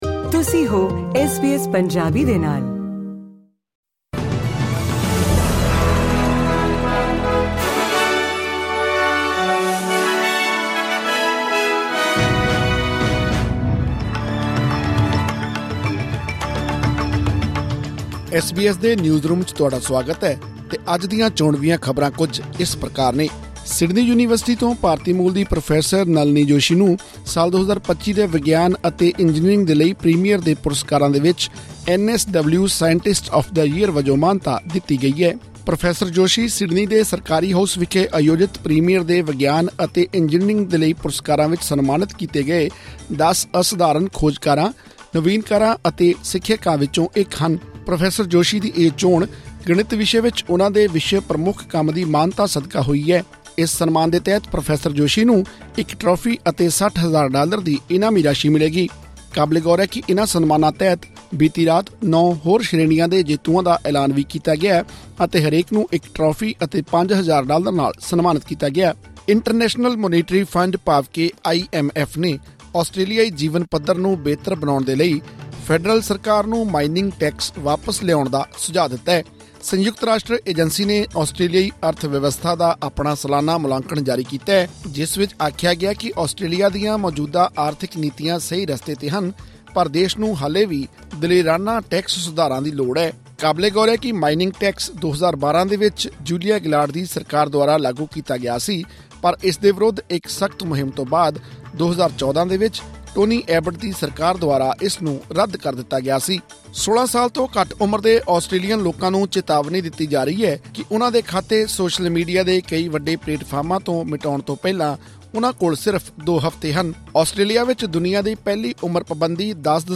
ਇਹ ਅਤੇ ਹੋਰ ਖਾਸ ਖਬਰਾਂ ਲਈ ਸੁਣੋ ਇਹ ਆਡੀਓ ਰਿਪੋਰਟ...